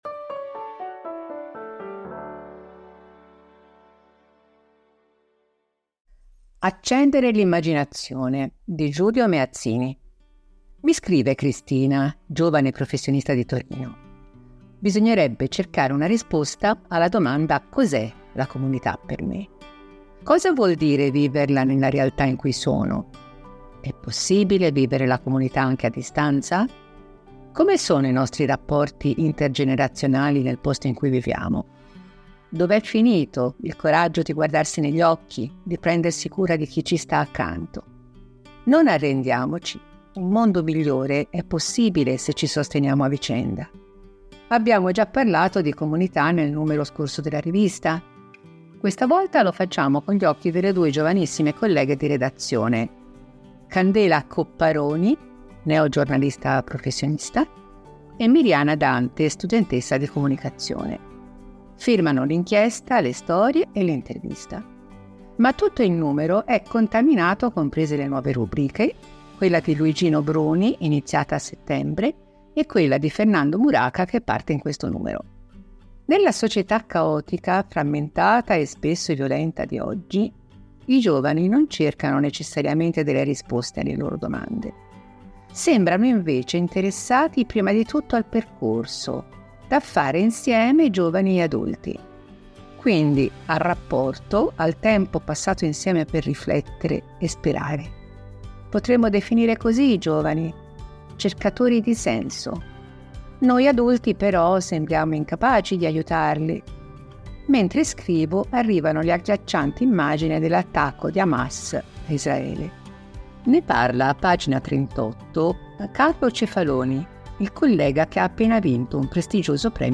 Per ogni numero, ci sarà una selezione di articoli letti dai nostri autori e collaboratori.
Al microfono, i nostri redattori e i nostri collaboratori.